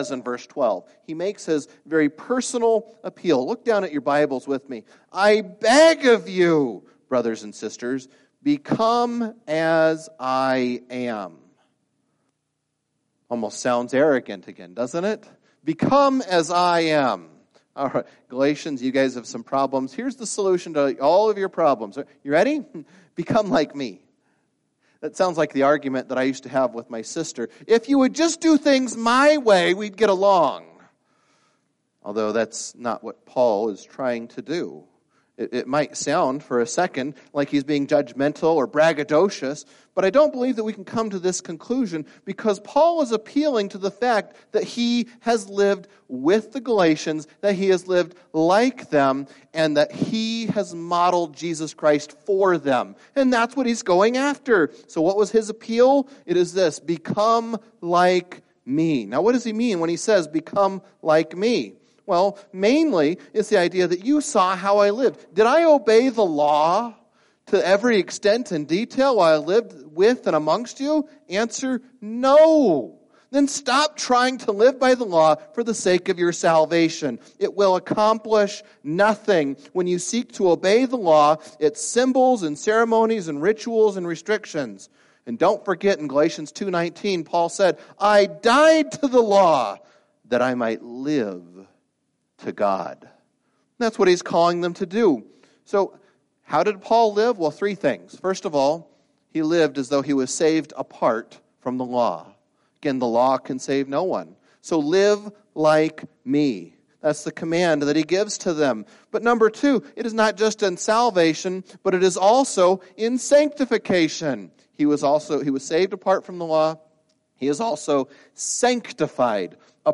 Past Sermons - Kuna Baptist Church